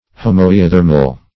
Homoeothermal \Ho`m[oe]*o*ther"mal\, a.